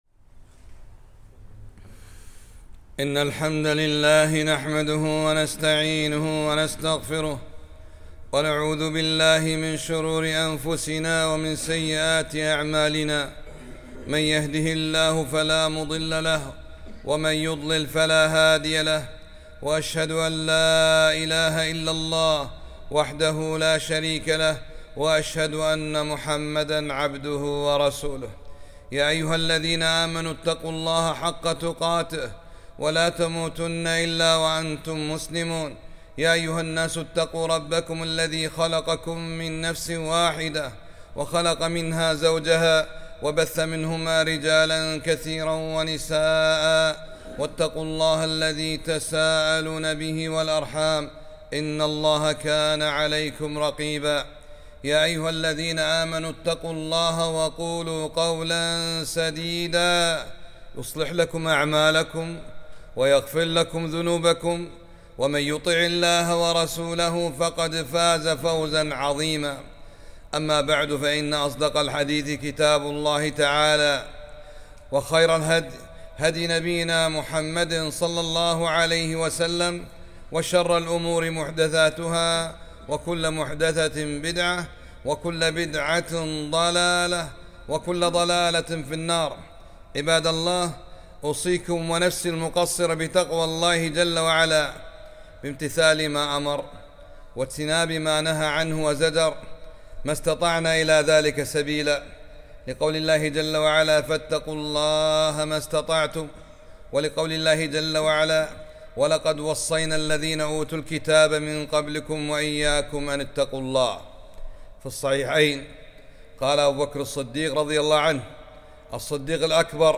خطبة - اللهم ظلمت نفسي ظلماً كثيراً